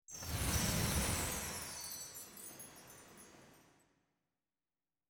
royal-sparkle-whoosh.mp3